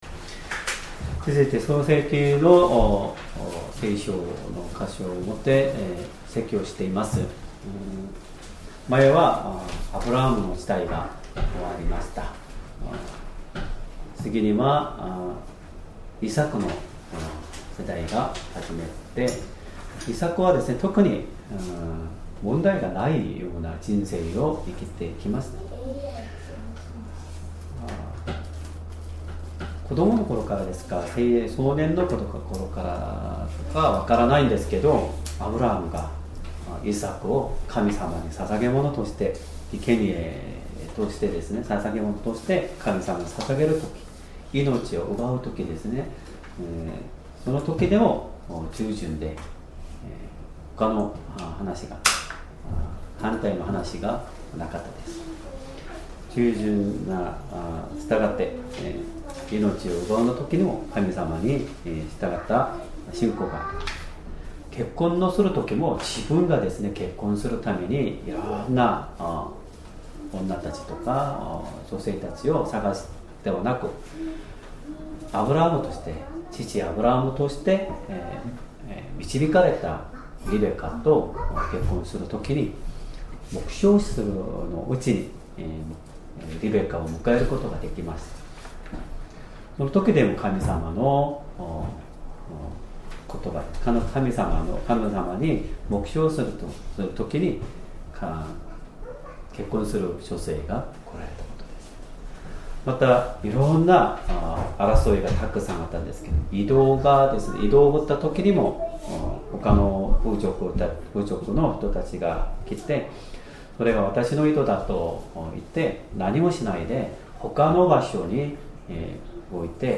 Sermon
2025年9月2８日 主日礼拝 説教 「 神様を礼拝するイサク」 聖書 創世記 26章 22-25 26:22 イサクはそこから移って、もう一つの井戸を掘った。